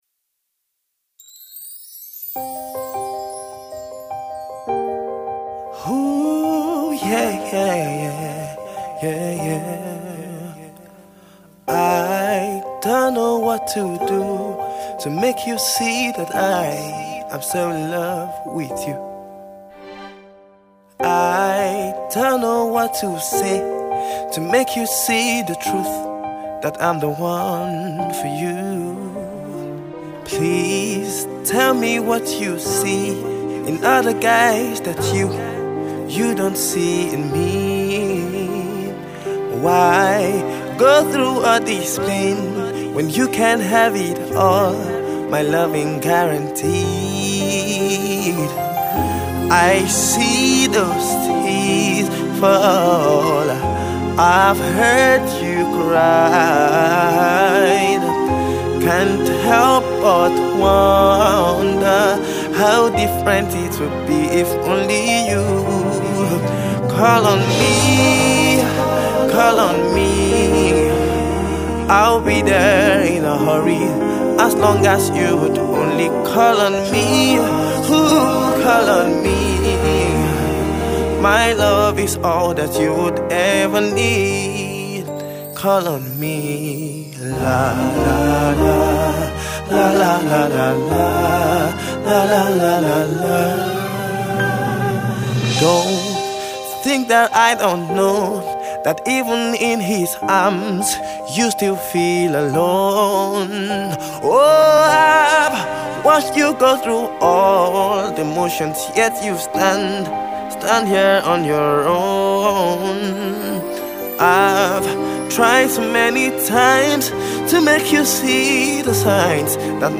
Soulful and heartfelt